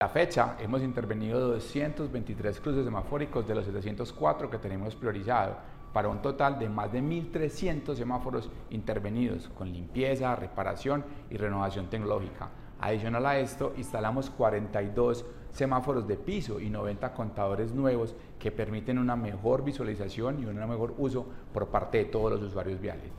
Declaraciones secretario (e) de Movilidad, Sergio Andrés Orozco La red semafórica del Centro de Medellín tiene una imagen renovada, gracias a la culminación del 100 % de las labores de limpieza y mantenimiento en sus 223 cruces viales.
Declaraciones-secretario-e-de-Movilidad-Sergio-Andres-Orozco.mp3